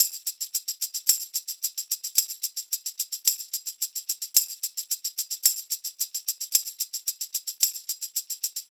Index of /m8-backup/M8/Samples/Loops/Perc Construction Loops/Tambourine